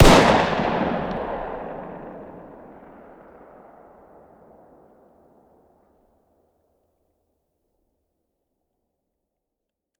fire-dist-10x25-pist..>2024-09-10 22:10 507K